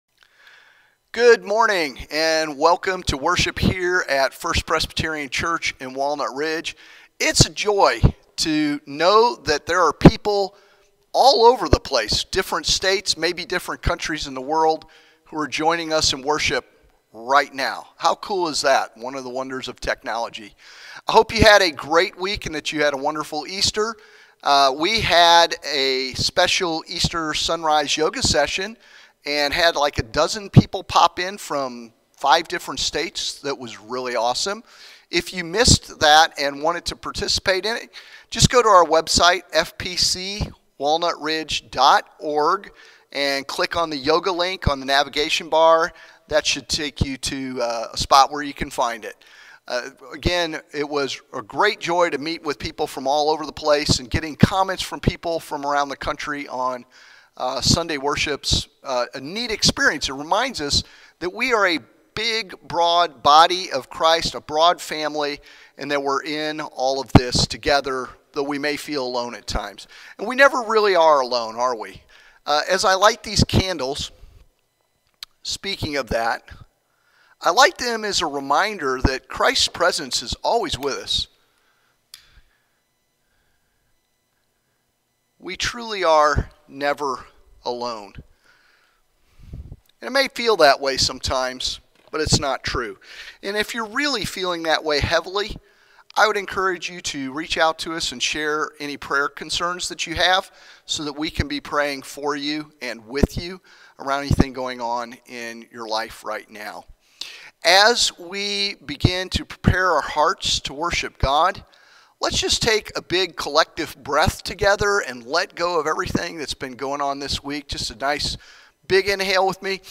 Find out what that is in today’s sermon as we explore Jesus encounters with the disciples in the upper room as well as doubting Thomas.